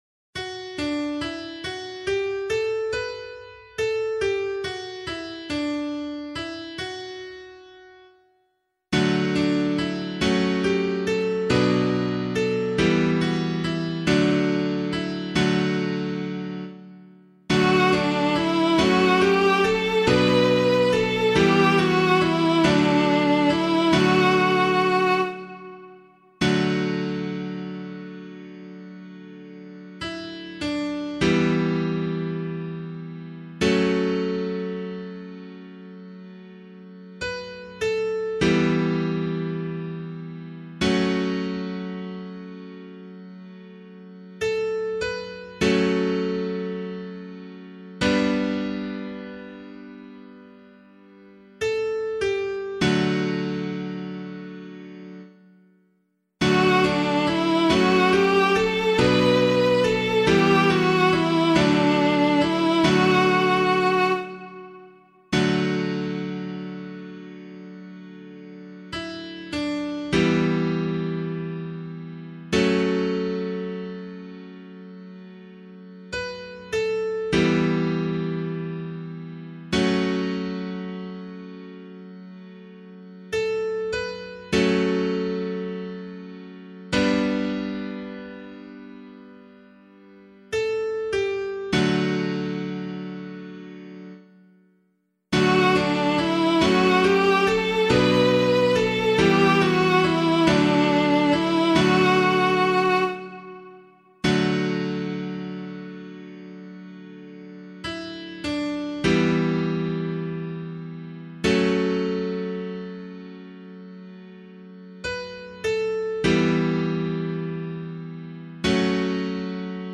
033 Trinity Psalm B [LiturgyShare 5 - Oz] - piano.mp3